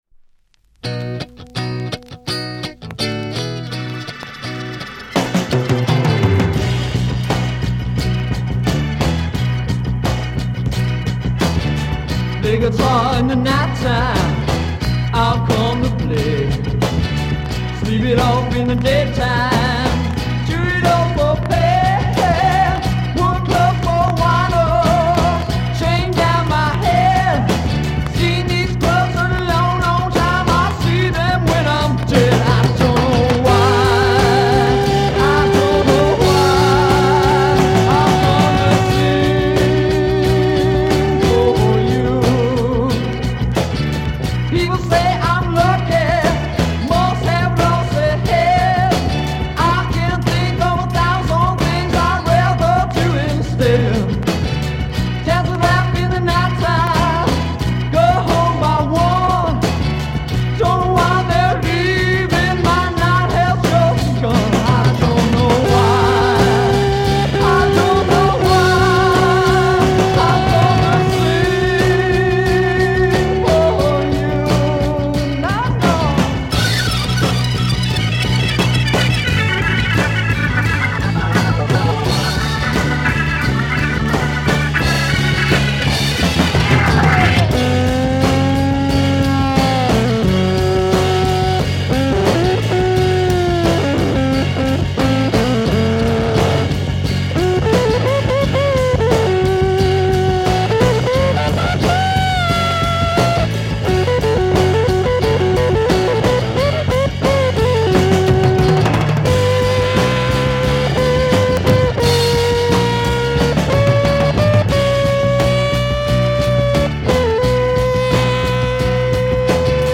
US Heavy psych garage dancer Break/acid fuzz